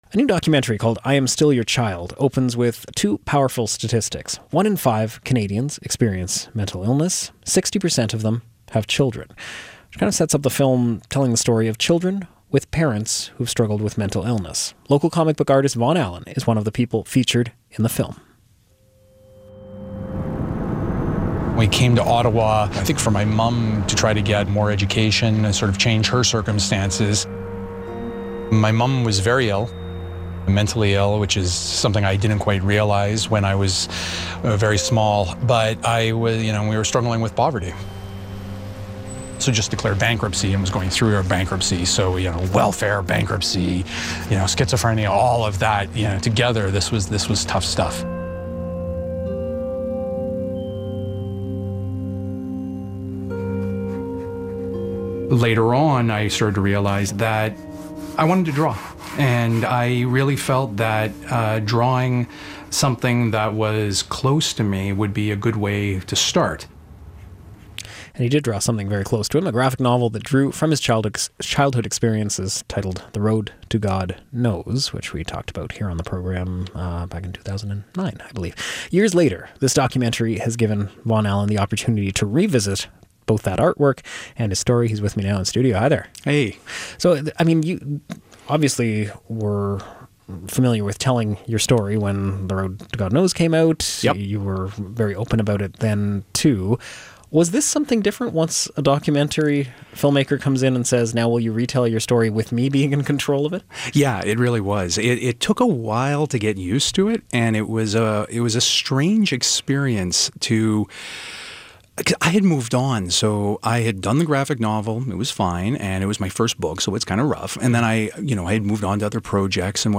Lightly Edited Transcript